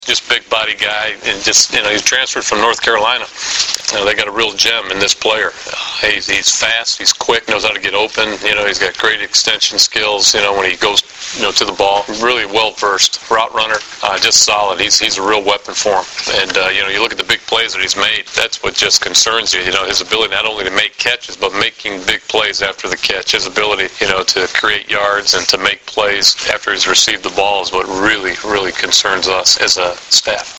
The following are audio links to press conference interview segments with Husker players and NU head coach Bill Callahan.
Head Coach Bill Callahan